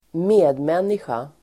Uttal: [²m'e:dmen:isja]